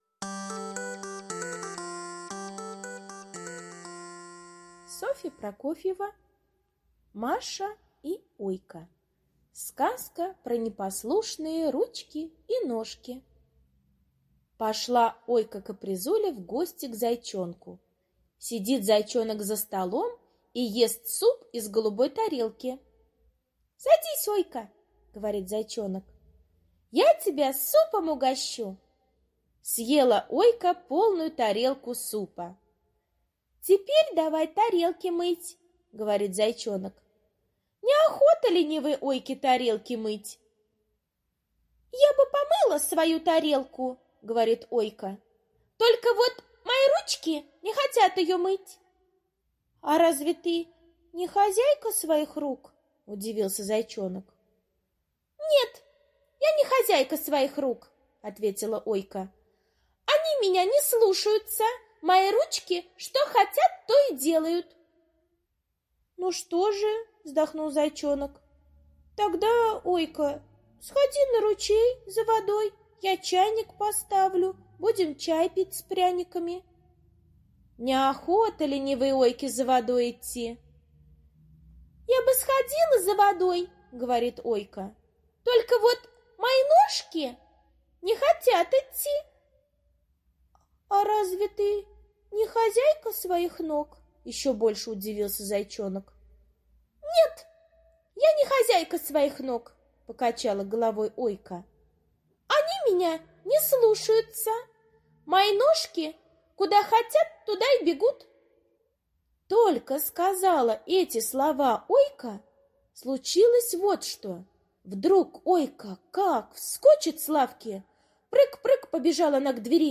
Маша и Ойка. Сказка про непослушные ручки и ножки (аудиоверсия)
Аудиокнига в разделах